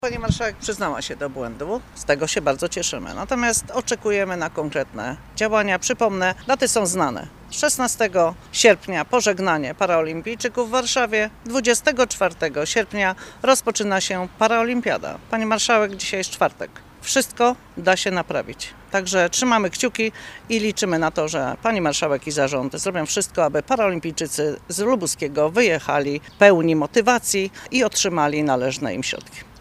Sprawę komentowali dziś radni Samorządowego Lubuskiego. Wioleta Haręźlak wezwała zarząd województwa i marszałek Elżbietę Polak do wypłacenia pieniędzy sportowcom jeszcze przed ich wylotem do Tokio.